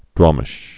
(dwämĭsh)